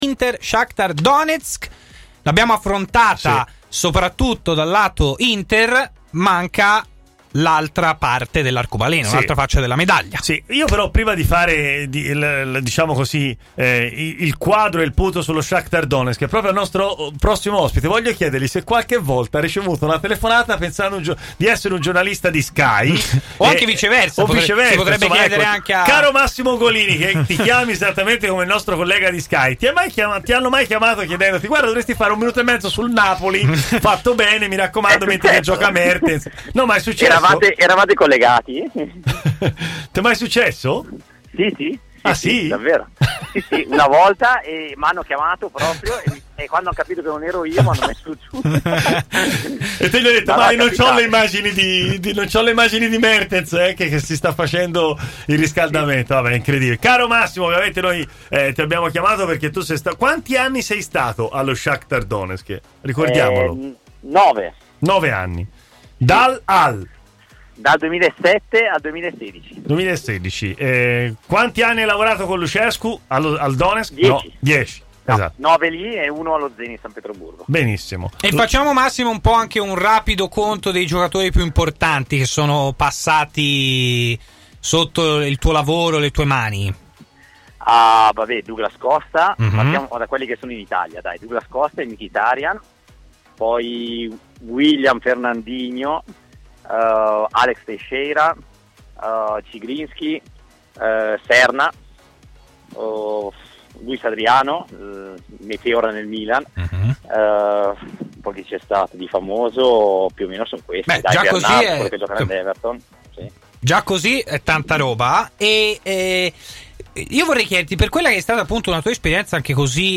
è intervenuto in diretta nel corso di Stadio Aperto, trasmissione di TMW Radio